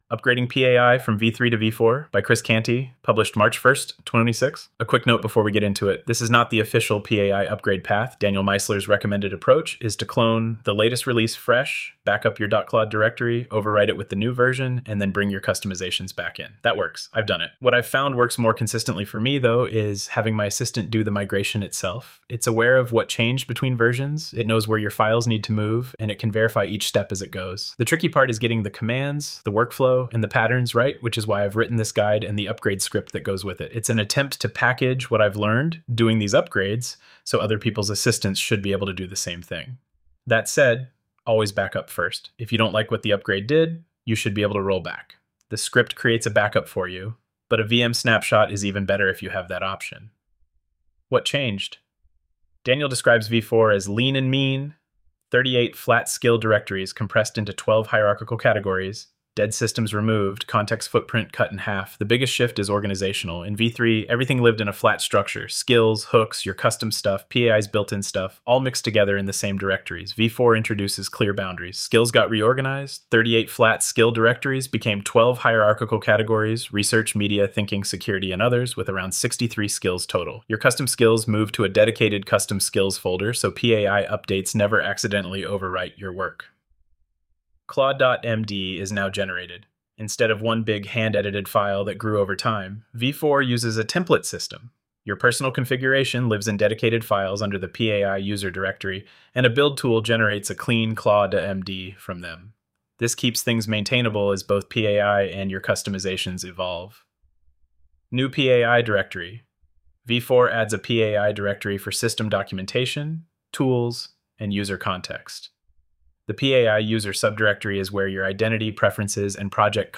AI-generated narration